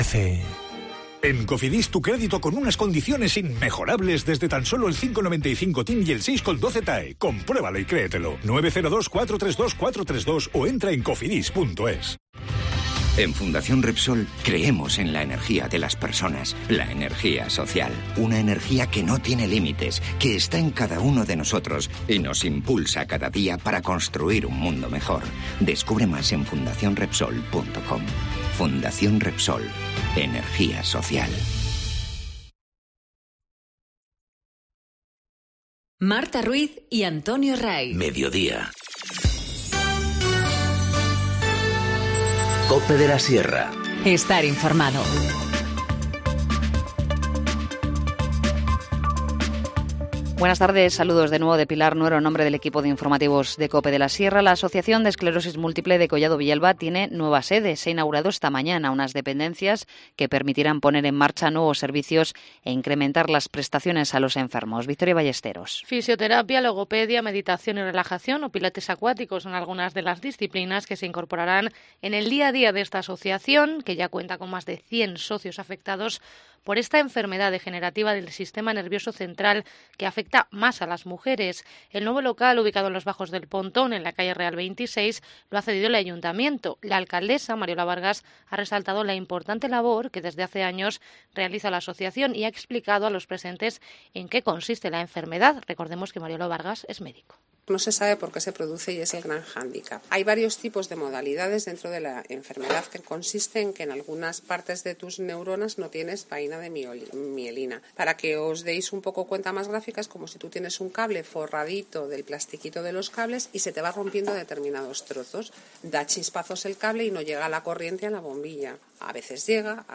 Informativo Mediodía 15 nov- 14:50h